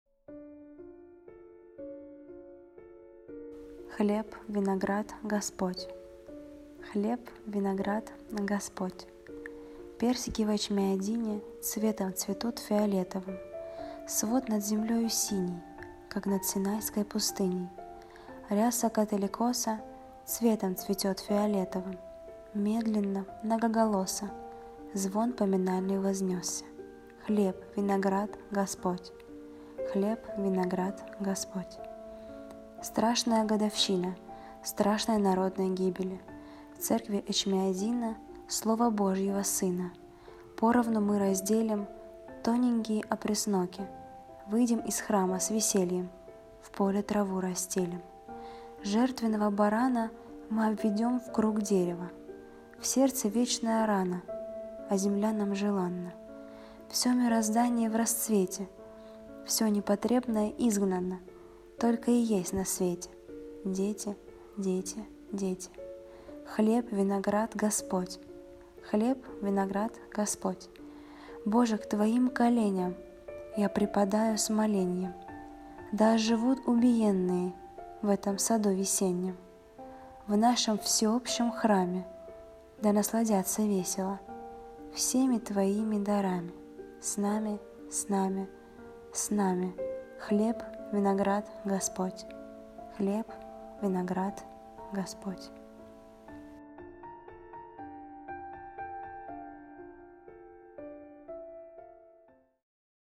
Песни на стихотворение:
1. «Folk group – Семен Липкин – Годовщина армянского горя» /